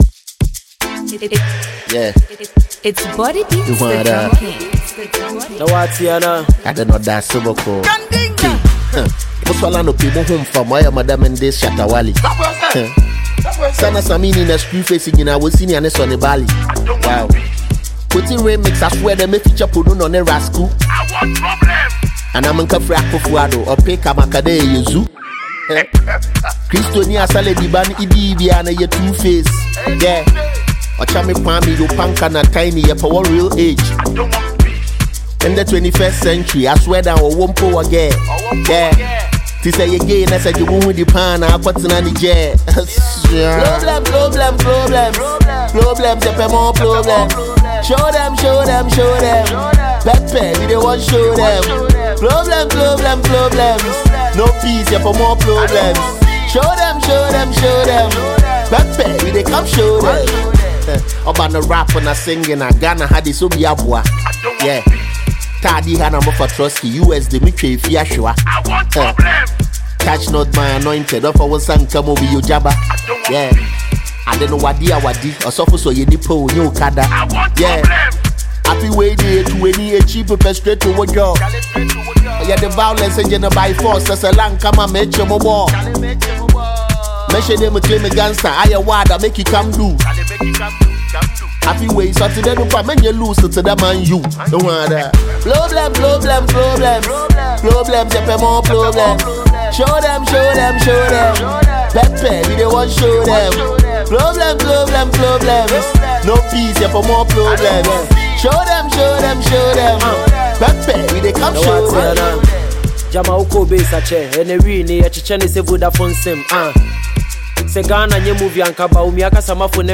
Ghanaian talented rapper